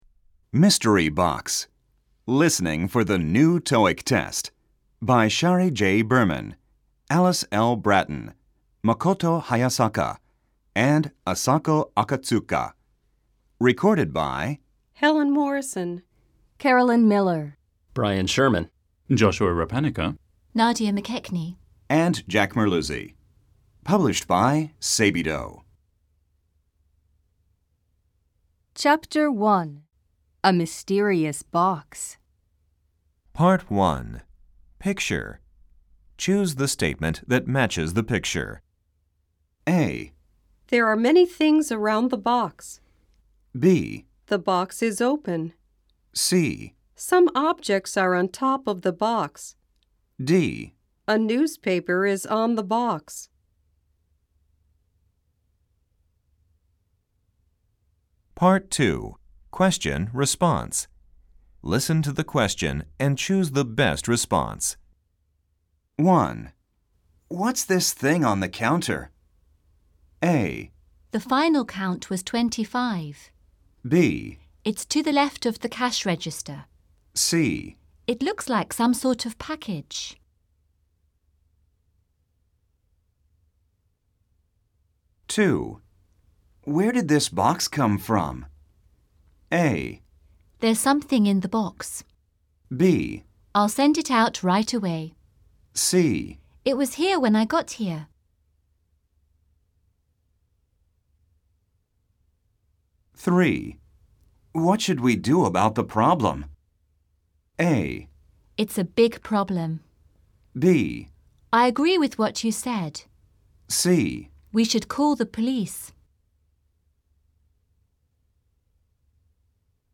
ジャンル リスニング副教材 / TOEIC® L&R TEST
吹き込み Amer E ／ Brit E